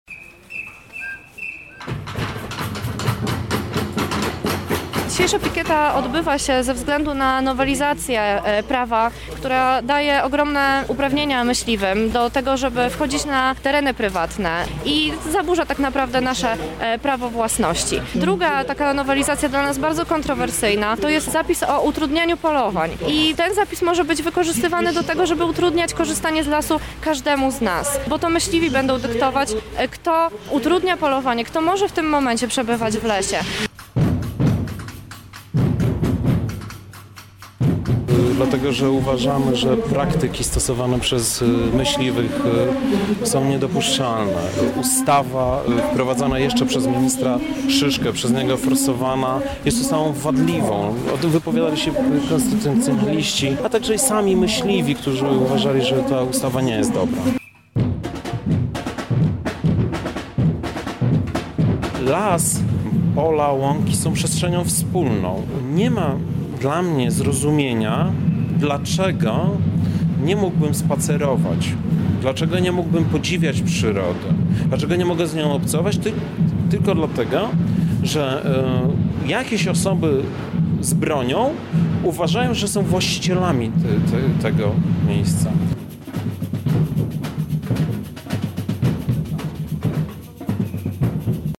Protest organizacji Lublin Przeciw Myśliwym pod siedzibą okręgowego Polskiego Związku Łowieckiego
Dlatego postanowili oni, pod siedzibą okręgowego Polskiego Związku Łowieckiego, wyrazić głośno swoje zdanie.